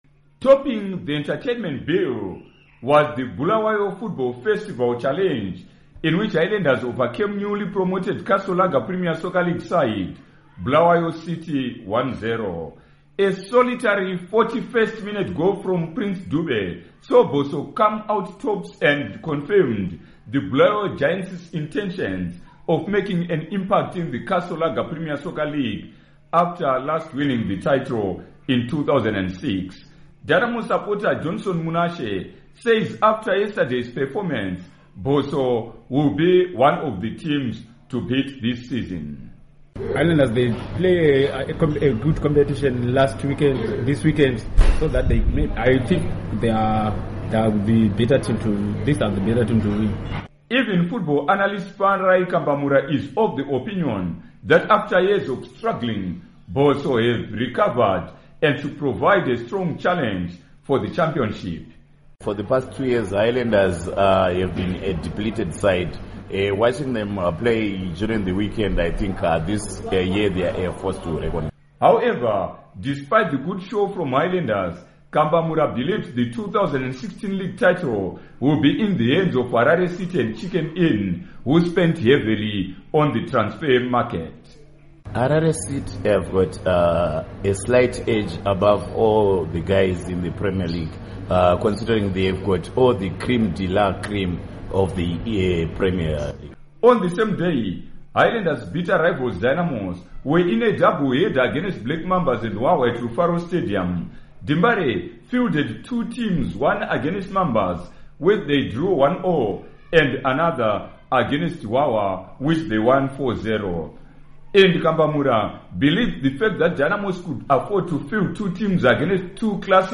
Report on Football Warm Up Games